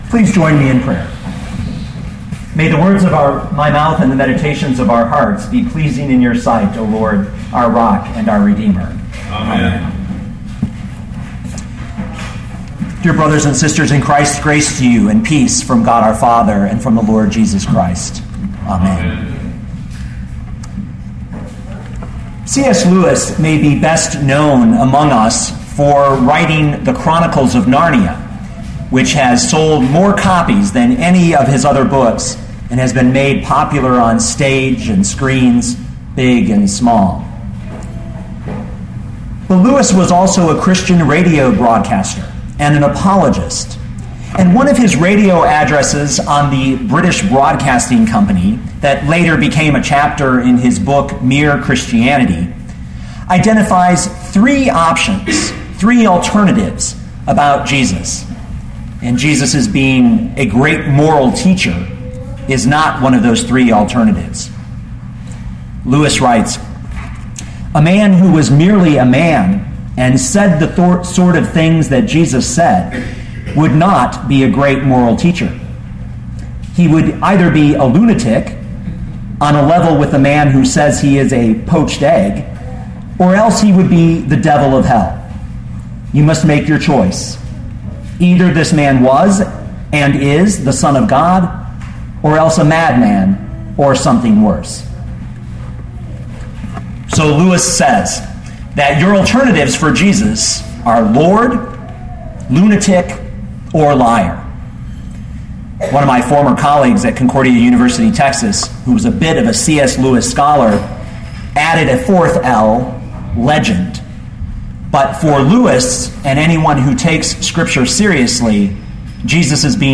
2013 John 8:48-59 Listen to the sermon with the player below, or, download the audio.